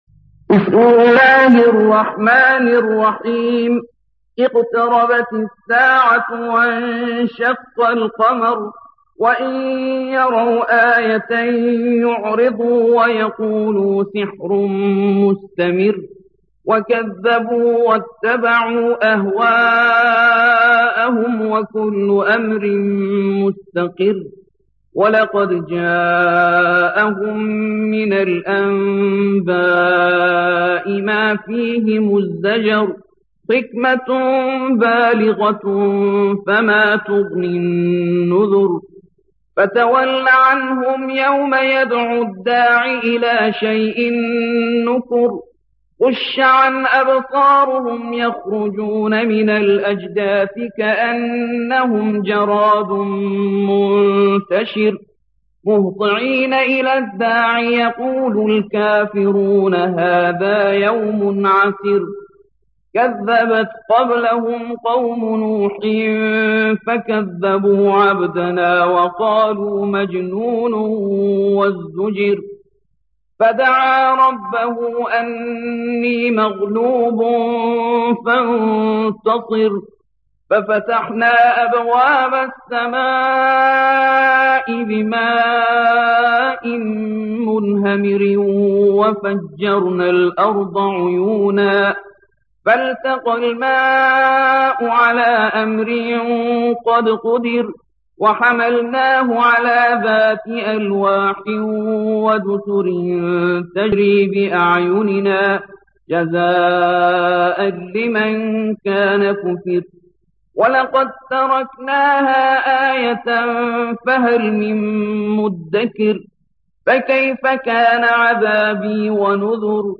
54. سورة القمر / القارئ